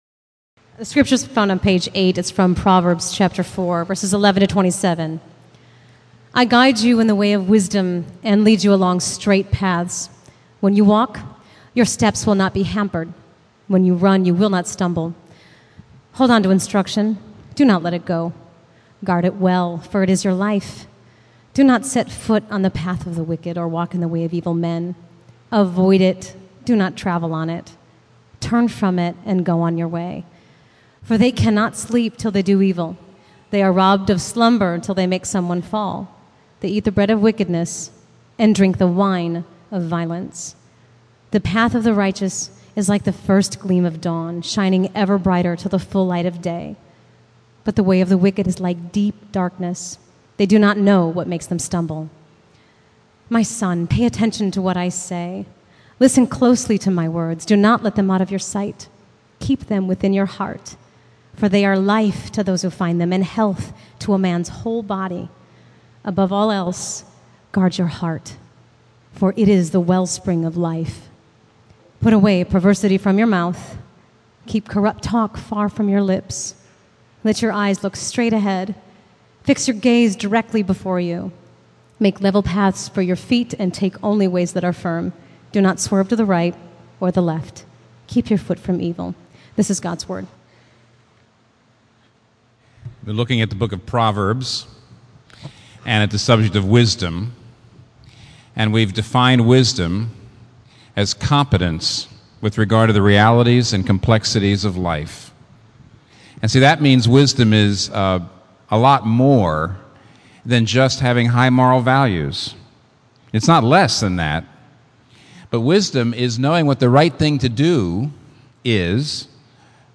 Sermons | Local Church